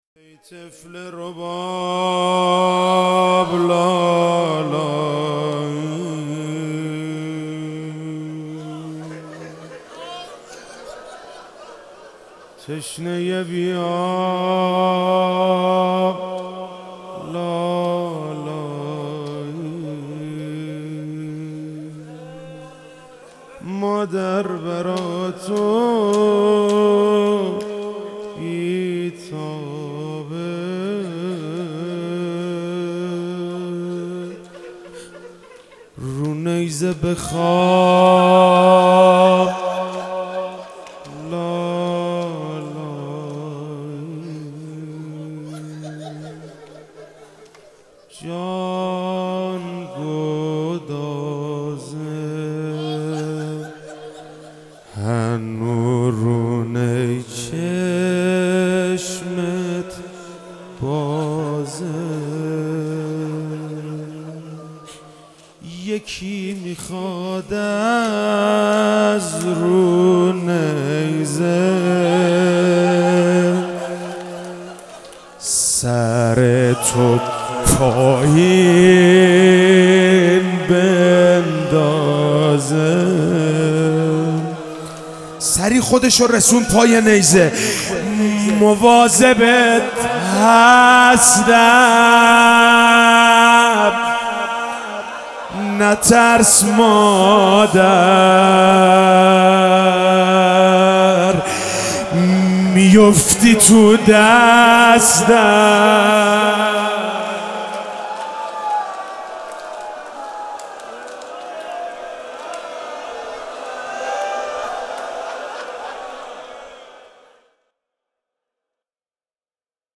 محرم 98 شب هفتم - روضه - ای طفل رباب لالایی